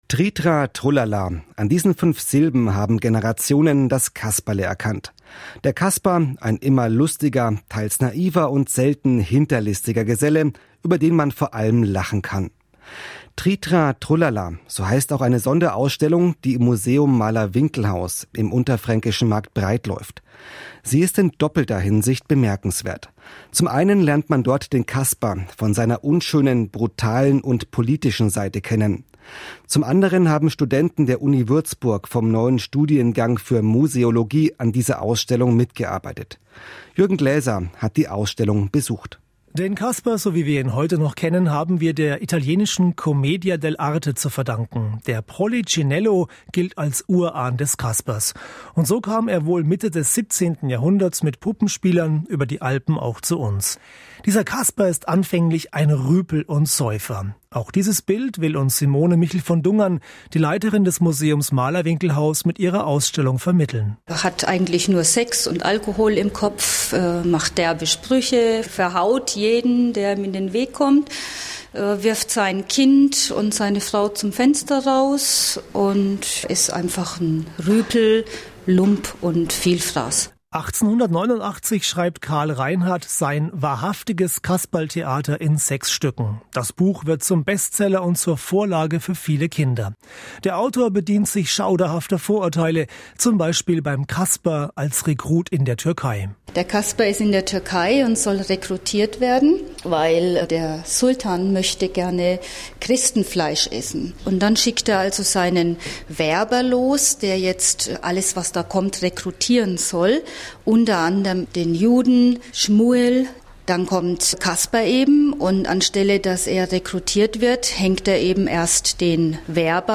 Ein Mitschnitt des BR,